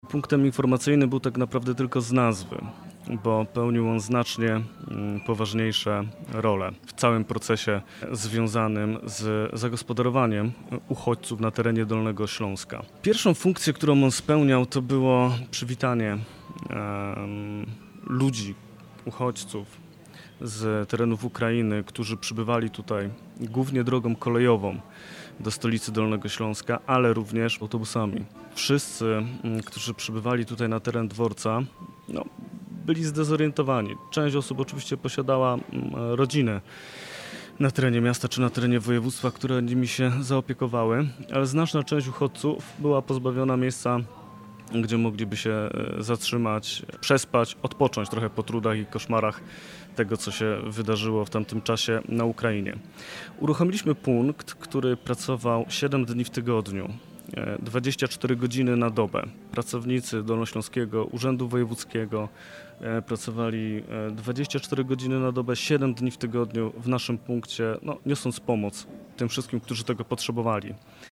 Nadajemy program prosto z Dworca Głównego PKP we Wrocławiu, z kawiarni Stacja Dialog.